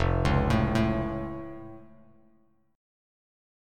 F+ Chord
Listen to F+ strummed